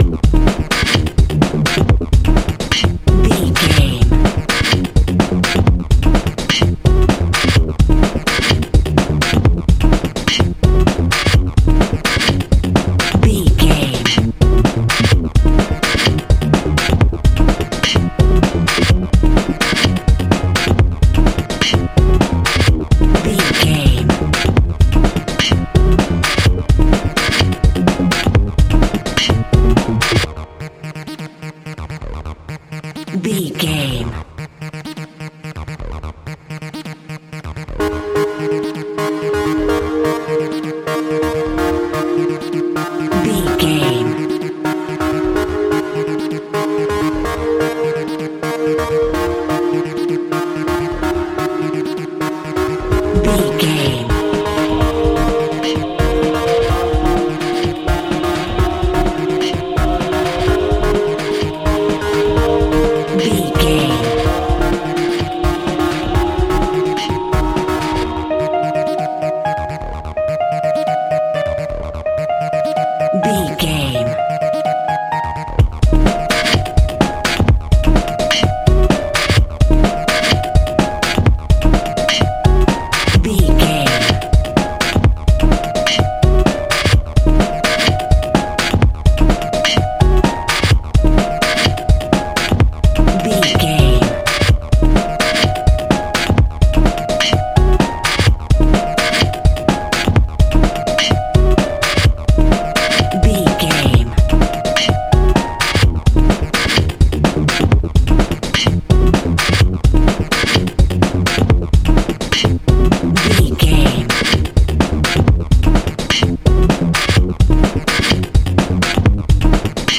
Industrial Chemical Music Beats.
Aeolian/Minor
E♭
driving
uplifting
futuristic
hypnotic
mechanical
drum machine
synthesiser
electronic
techno
synthwave